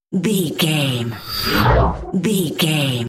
Sci fi horror whoosh fast
Sound Effects
Atonal
Fast
scary
ominous
eerie
whoosh